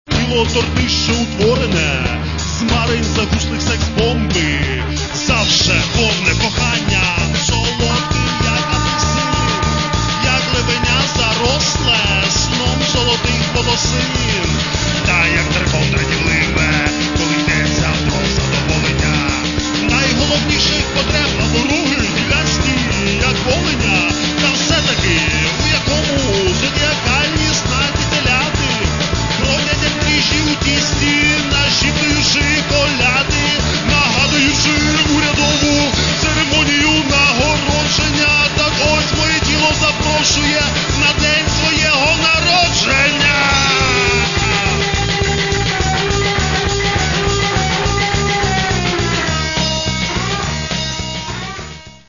Рок та альтернатива